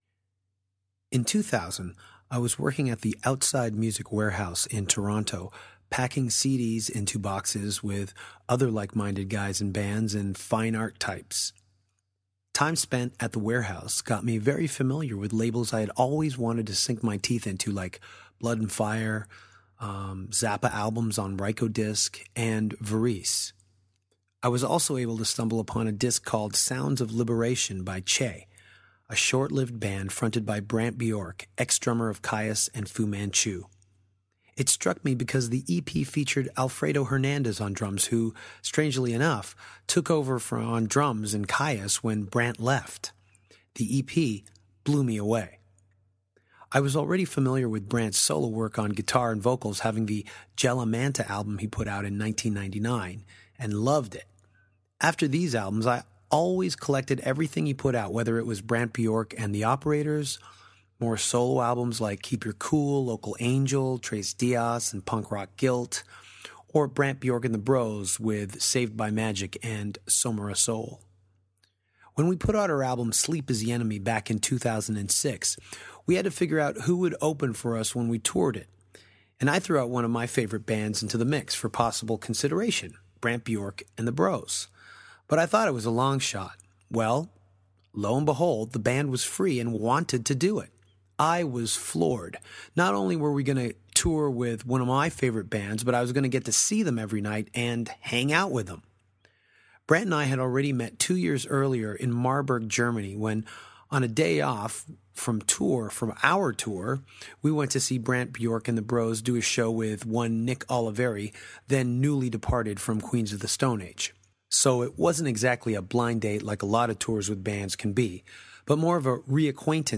Danko sat down with Brant Bjork at The Soundwave Festival in Australia to talk about Kyuss Lives, Vista Chino, Mike Dean and growing up in the desert versus growing up in Toronto.